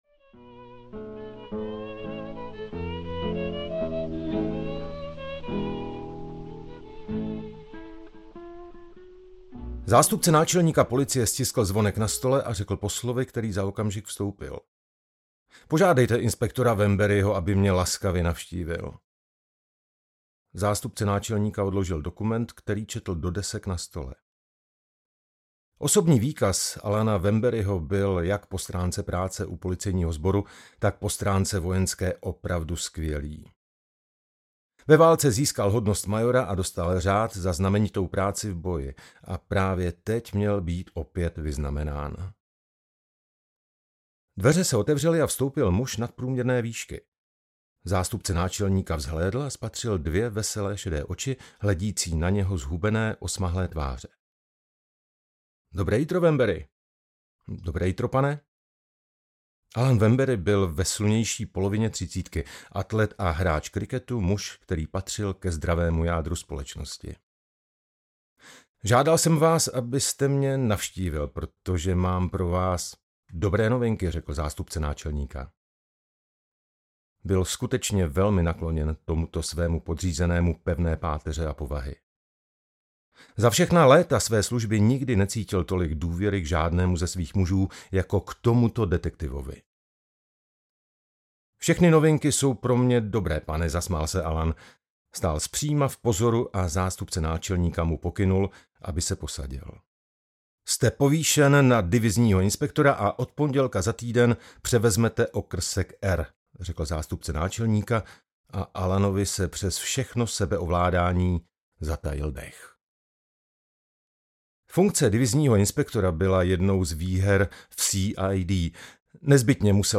Maska audiokniha
Ukázka z knihy